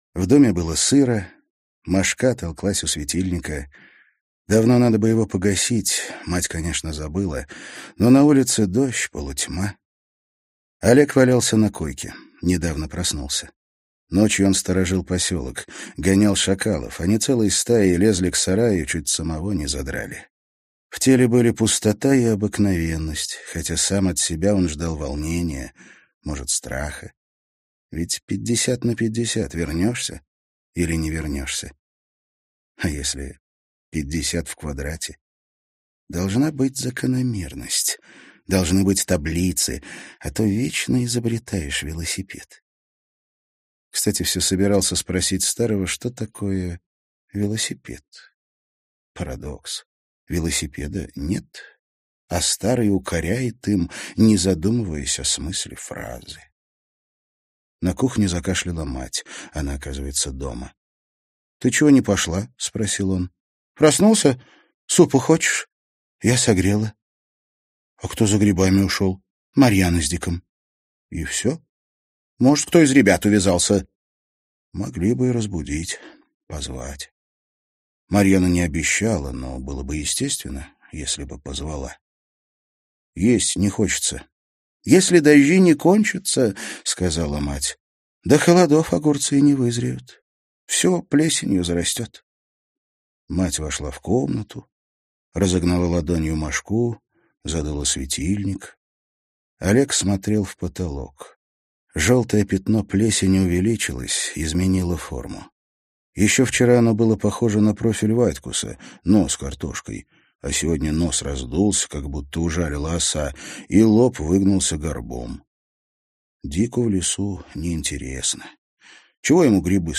Прослушать фрагмент аудиокниги Посёлок Кир Булычев Зарубежная фантастика Научная фантастика Повести, Рассказы Произведений: 29 Скачать бесплатно книгу Скачать в MP3 Вы скачиваете фрагмент книги, предоставленный издательством